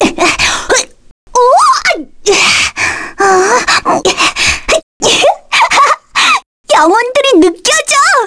Rephy-vox-get_kr.wav